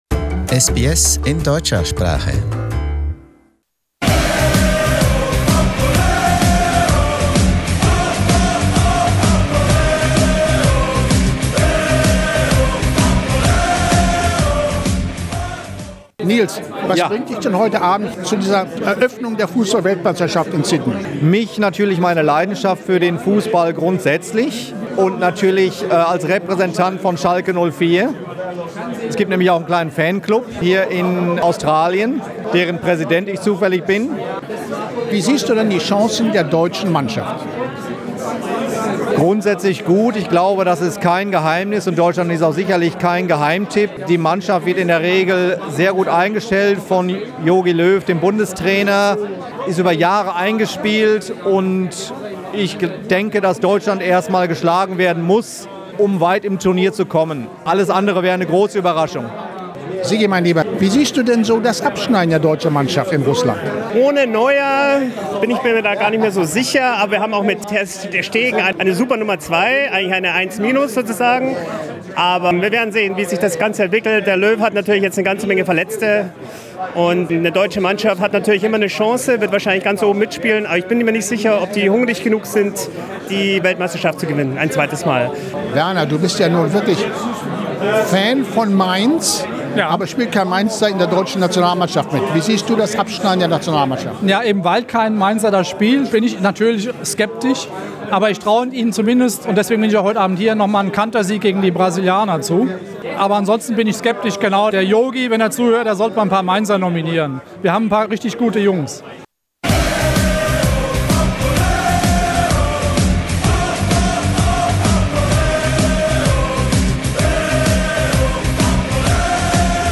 Germany and Brazil are two of the hot favorites for the upcoming FIFA Wold Cup in Russia next month. In Sydney, the chambers of commerce of the two countries couldn't wait any longer for the big event. They got their fans together for a lively a good-natured discussion over drinks with some well know Australian football experts.